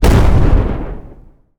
explosion_large_04.wav